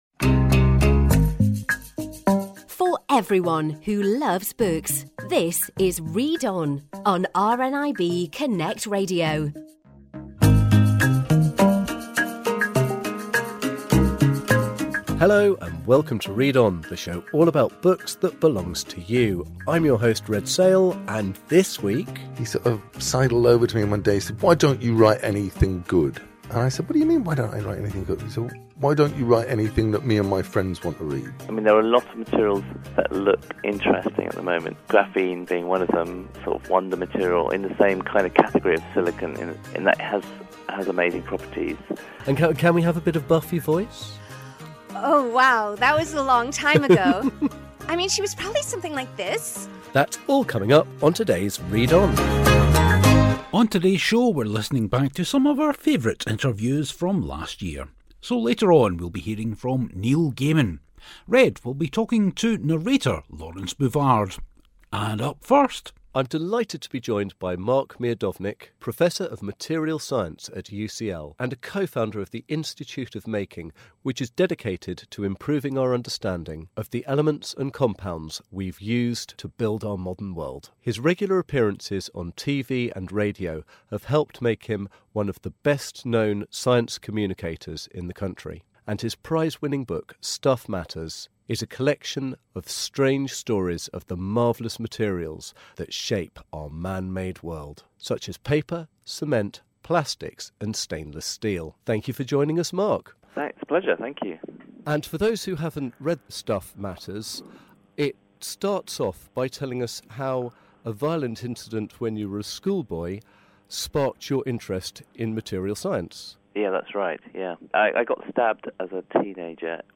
A listen back to three interviews from last year, one fiction, one non-fiction and one from an RNIB Talking Book narrator.